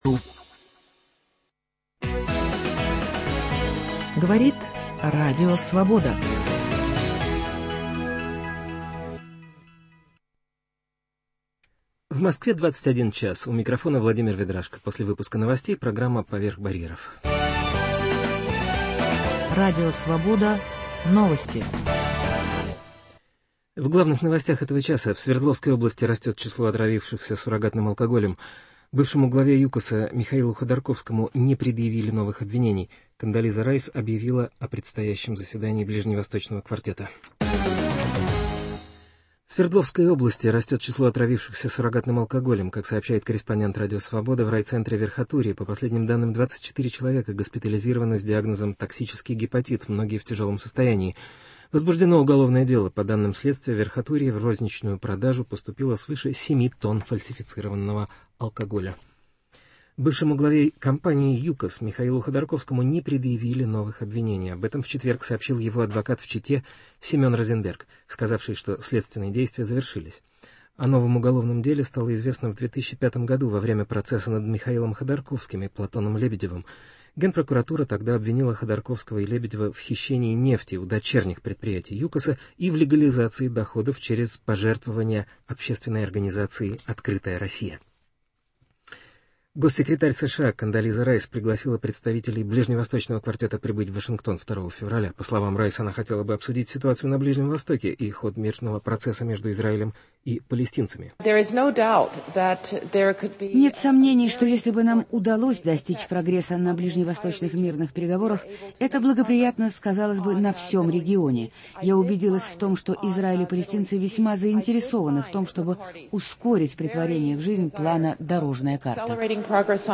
Интервью с Борисом Покровским к 95-летию великого режиссера. "Трамвай Желание" Генриетты Яновской - номинант "Золотой Маски".